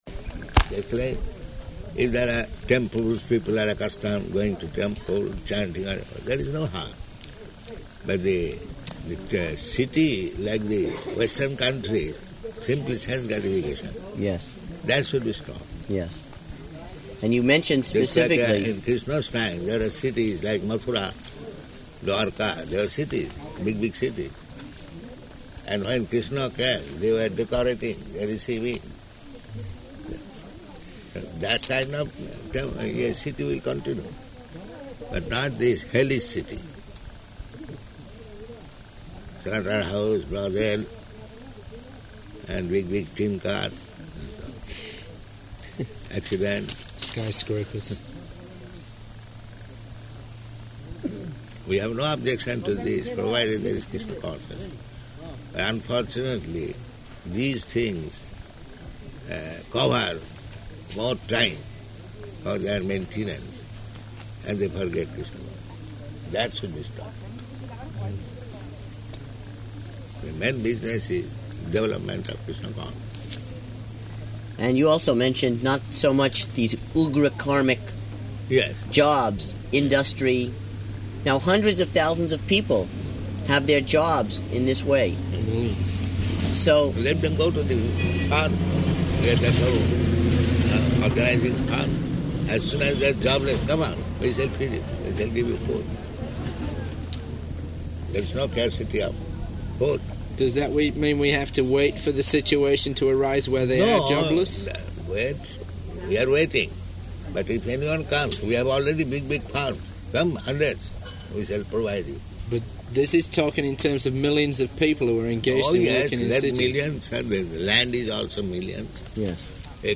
Room Conversation
Type: Conversation
Location: Bhubaneswar